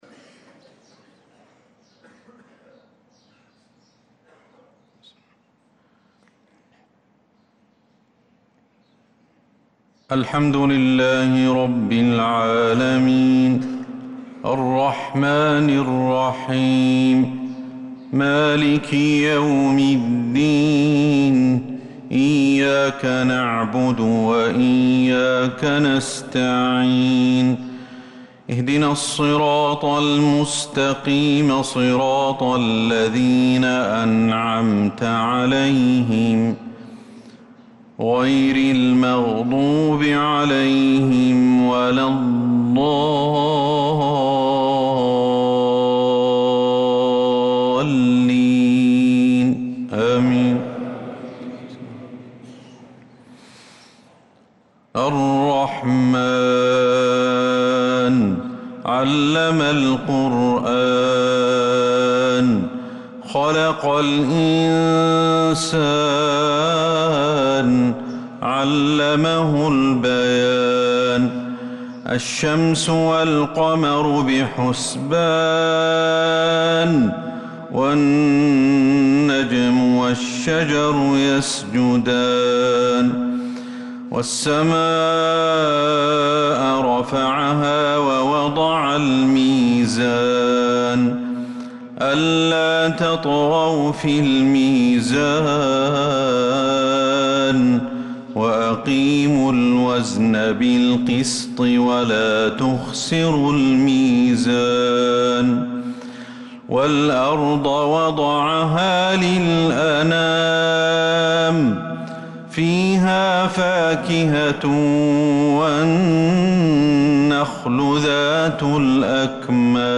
فجر الجمعة 3-7-1446هـ فواتح سورة الرحمن 1-61 | Fajr prayer from Surat Al-rahman 3-1-2025 > 1446 🕌 > الفروض - تلاوات الحرمين